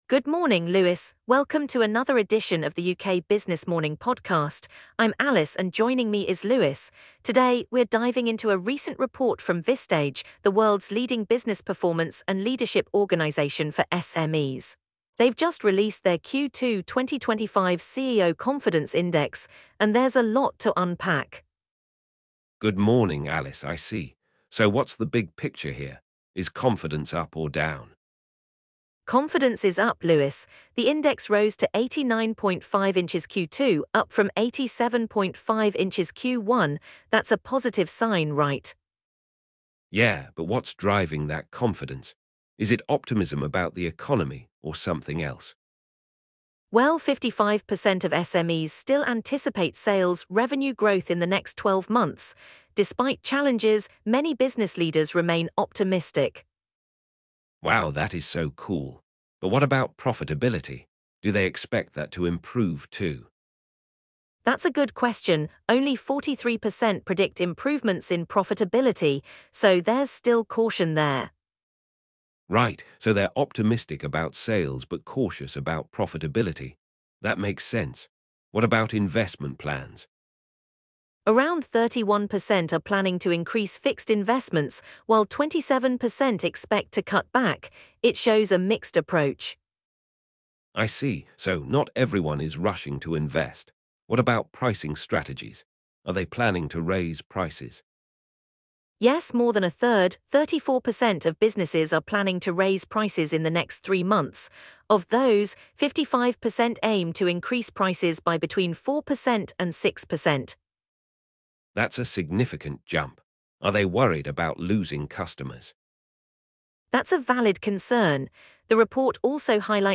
The conversation delves into pricing strategies, the impact of UK-EU trade developments, and the mental well-being of business leaders, providing valuable insights for navigating the complex business landscape in 2025.